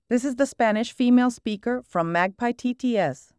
🔉ES-US.Female.Female-1
ES-US.Female.Female-1_MagpieTTS.wav